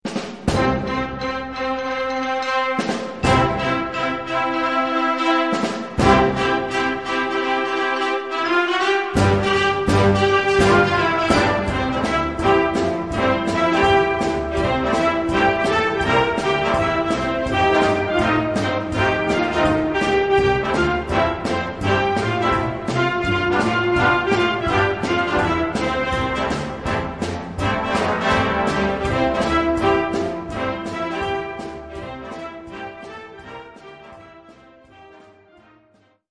Gattung: Moderner Einzeltitel
Besetzung: Blasorchester
Rock´n Roll auf der Straße